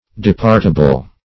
Departable \De*part"a*ble\, a.